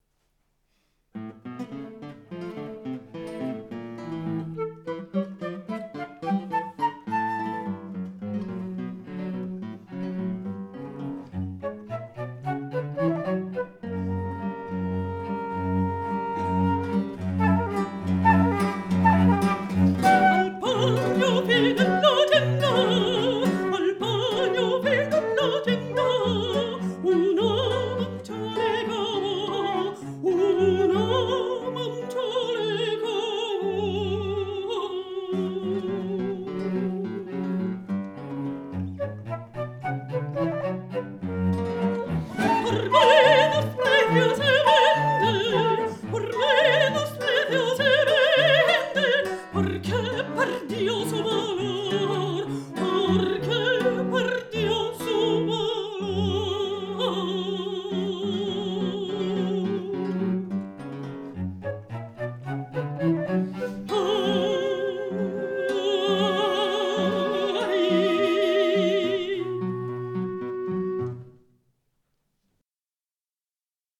mezzosoprano
flauto
violoncello
chitarra
Archivio Storico della Città di Torino
Live recording, Giugno 2007